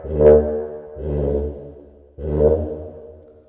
描述：普蒂普是一种打击乐器，用于那不勒斯的民间音乐，一般来说，用于意大利南部大部分地区的民间音乐。(另一个名字是"caccavella"。)普蒂普这个名字是乐器演奏时发出的"打嗝"的拟声词。该乐器由一个横跨共鸣室的薄膜组成，就像一个鼓。然而，膜不是被卡住的，而是用一个手柄在腔内有节奏地压缩空气。然后，空气从将膜固定在乐器的粘土或金属主体上的不完全密封处喷出，声音很大。
Tag: caccavella 粘土 民族 民俗 那不勒斯 打击乐器 putip 摩擦